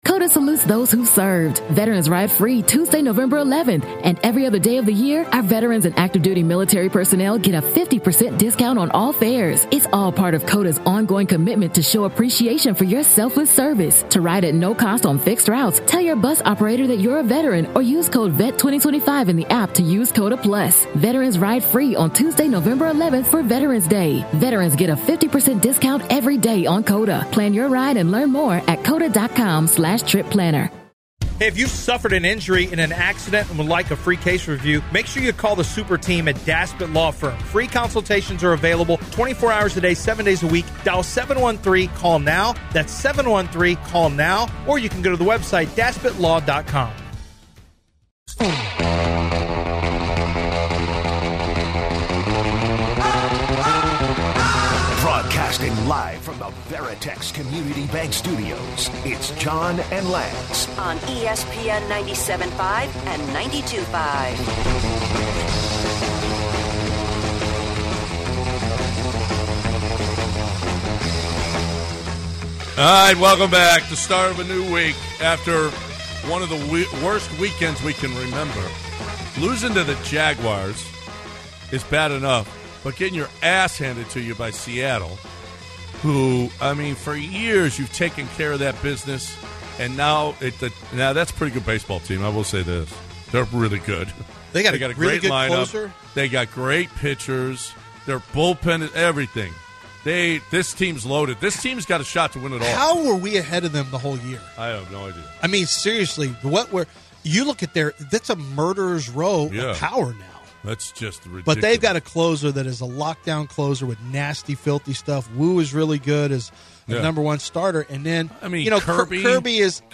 Callers give their thoughts